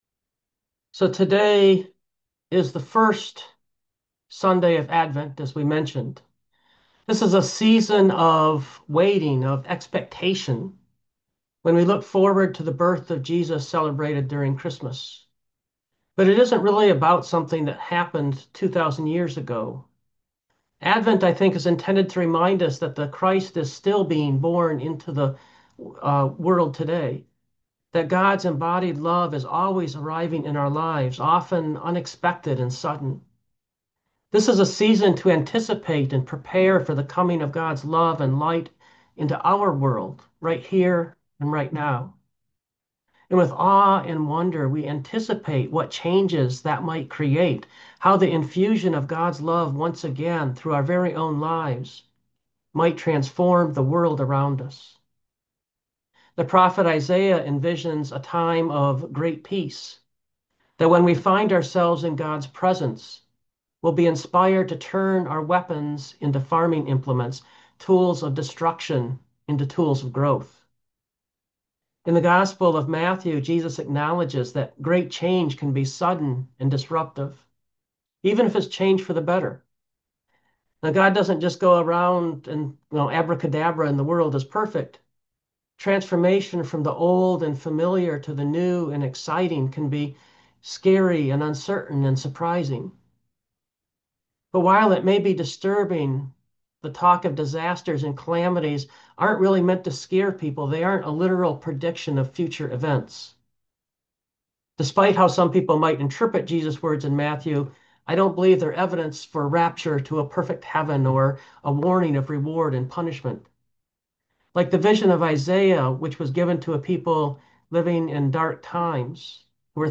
Preacher: